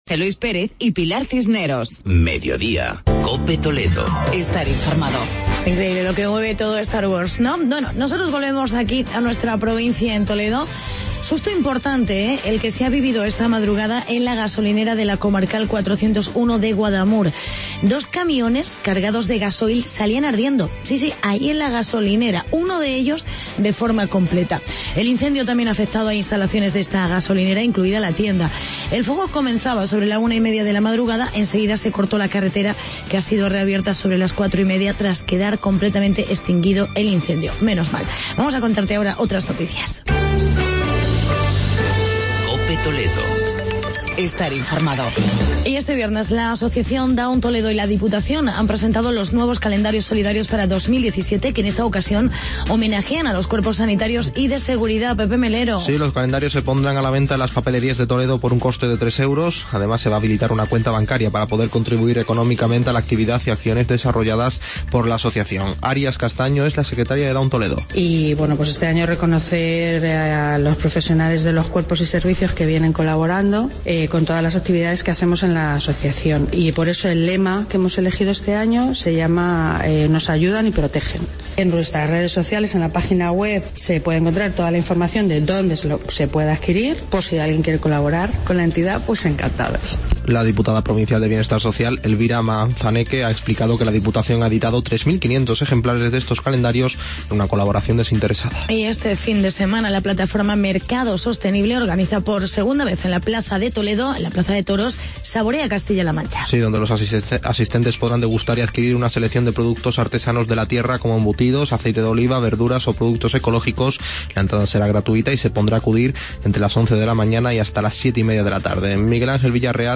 Actualidad y entrevista